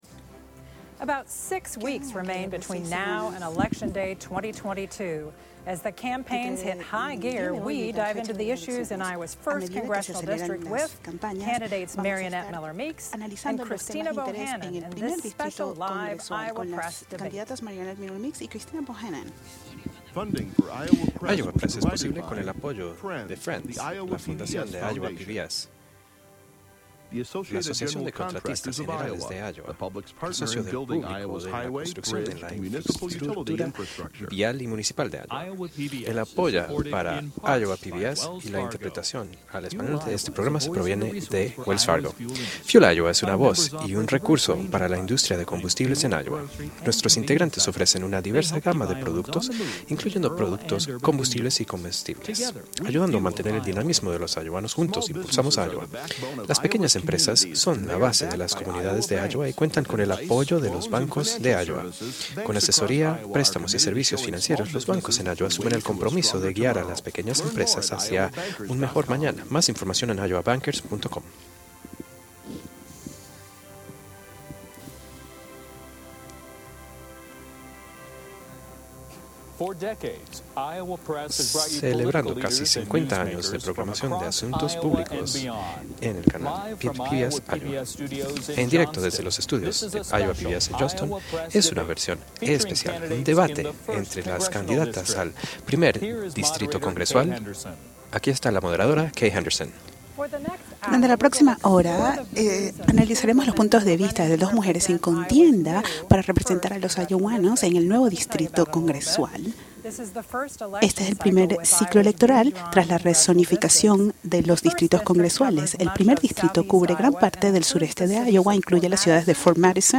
Debate de candidatas al primer distrito congresual de Iowa
Las candidatas, Christina Bohannan (D - Iowa City), representante en la cámara estatal de Iowa y la actual representante por Iowa en la Cámara Baja de los EE.UU., Mariannette Miller-Meeks (R - Ottumwa), responden preguntas de los reporteros y hablan acerca de sus plataformas, inquietudes y planes para el futuro.